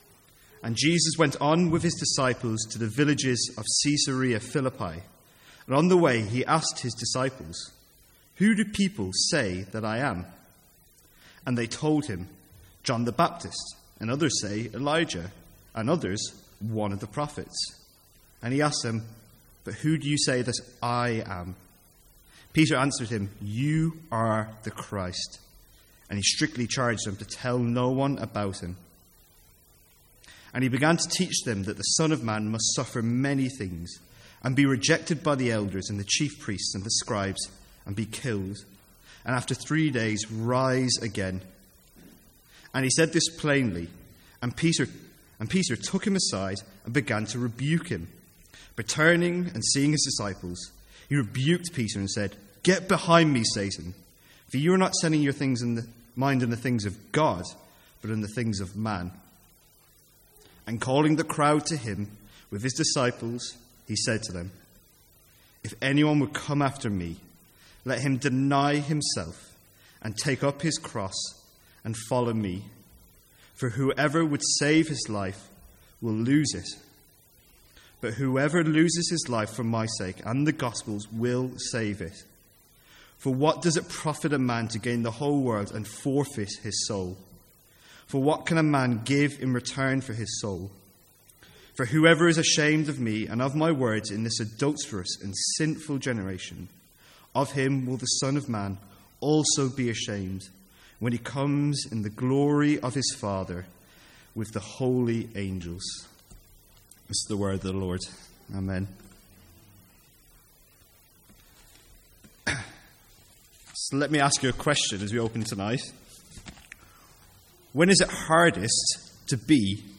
Sermons | St Andrews Free Church
From the Sunday evening series 'Hard sayings of Jesus'.